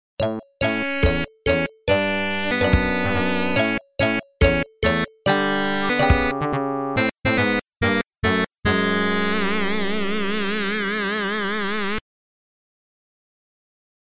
Звуки завершения, эффект
The End кинематографический